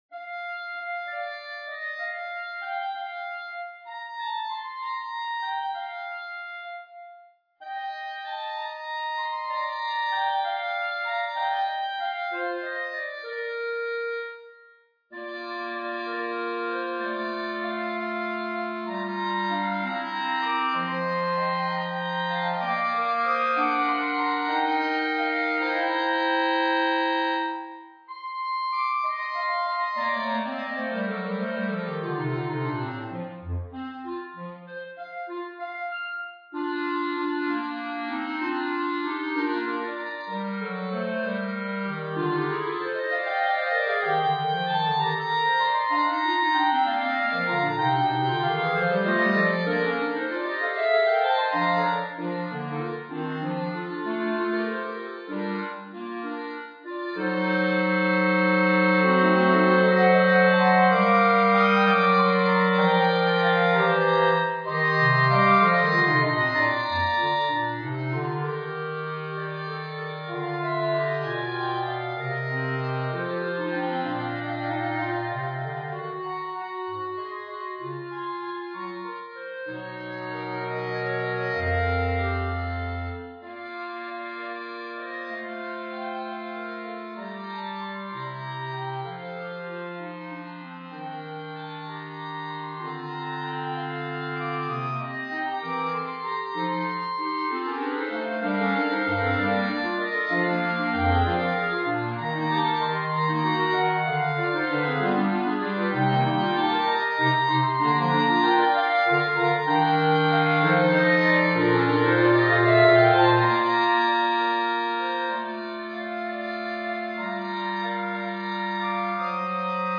B♭ Clarinet 1 B♭ Clarinet 2 B♭ Clarinet 3 Bass Clarinet
单簧管四重奏
童谣